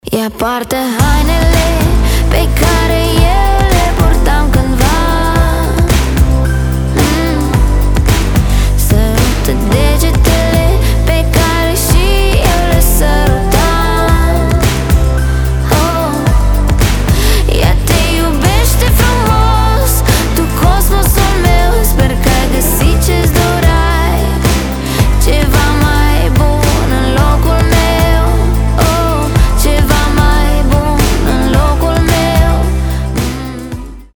• Качество: 320, Stereo
грустные
dance
красивый женский голос